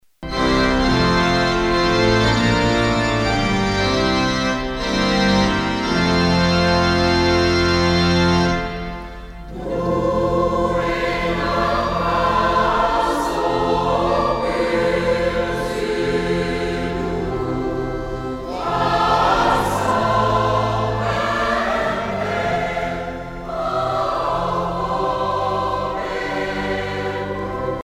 circonstance : dévotion, religion
Pièce musicale éditée